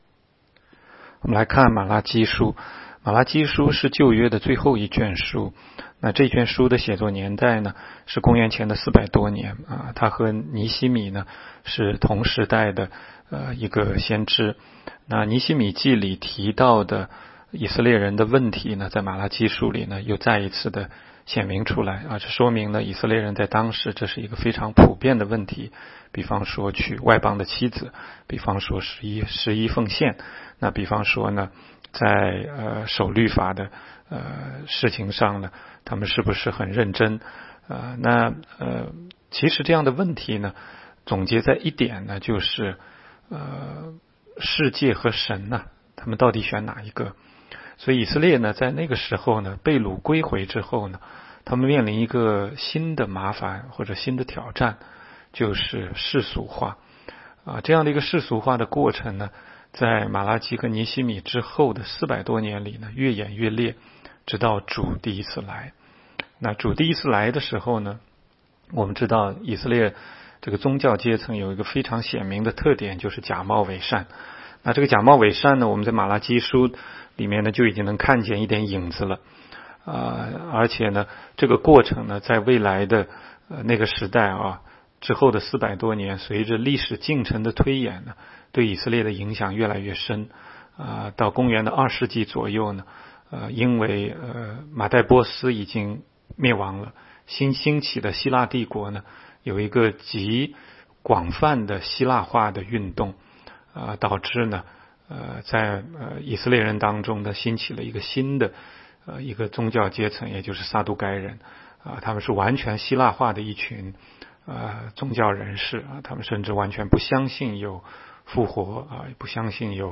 16街讲道录音 - 每日读经 -《玛拉基书》1章
每日读经